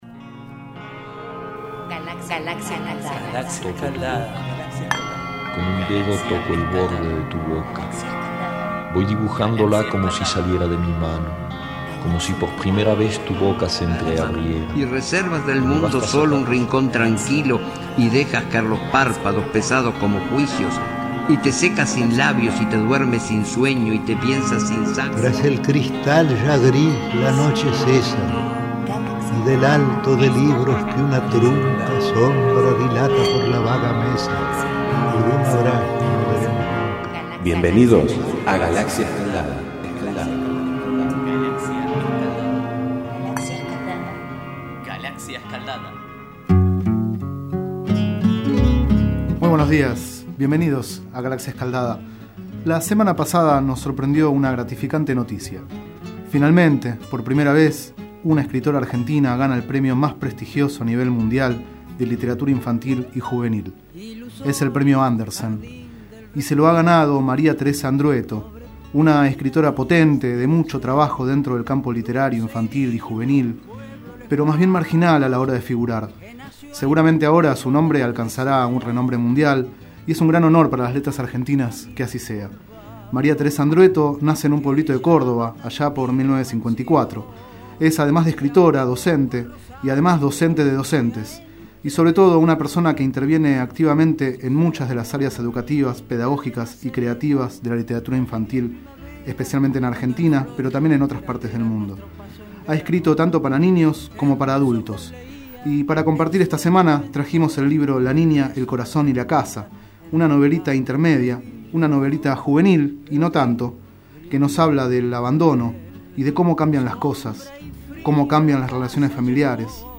4º micro radial, realizado el 26 de marzo de 2012, sobre el libro La niña, el corazón y la casa, de María Teresa Andruetto.
Este es el 4º micro radial, emitido en los programas Enredados, de la Red de Cultura de Boedo, y En Ayunas, el mañanero de Boedo, por FMBoedo, realizado el 26 de marzo de 2012, sobre el libro La niña, el corazón y la casa, de María Teresa Andruetto.